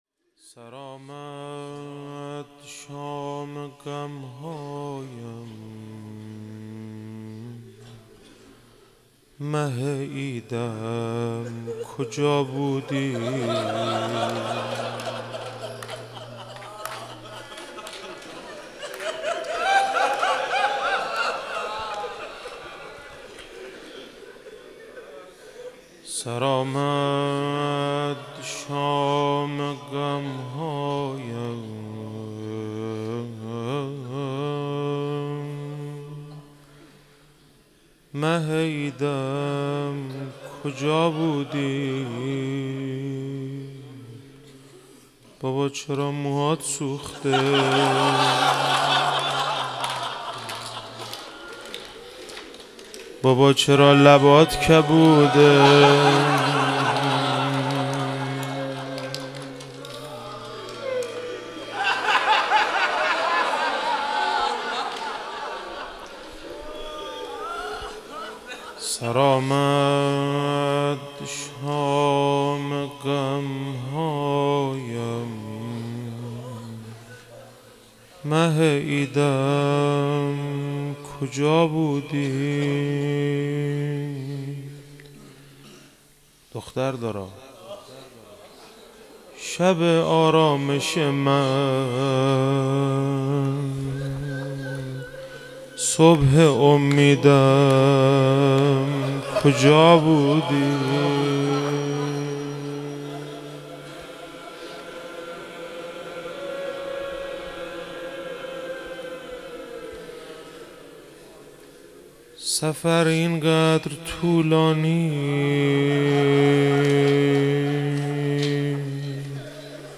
شهادت حضرت رقیه(س)97 - روضه - سر آمد شام غم هایم